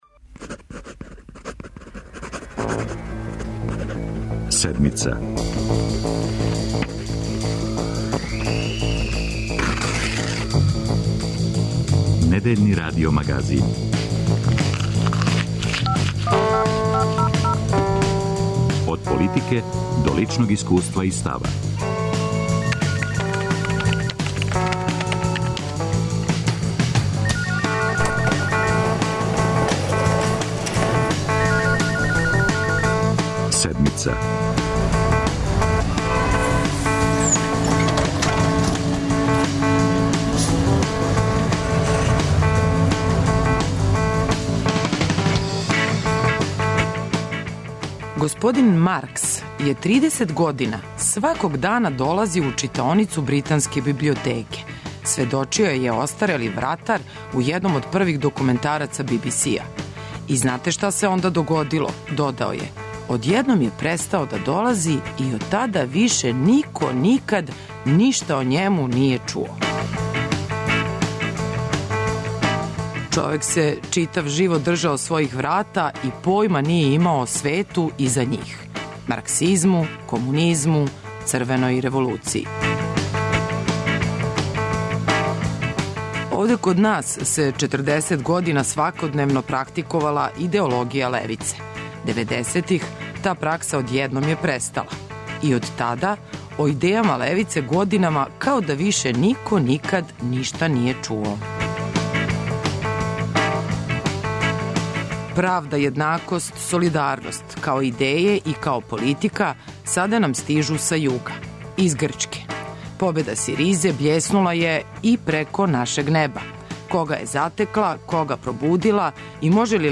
Од краха југословенске, до успона европске левице, за Седмицу говори Љубиша Ристић.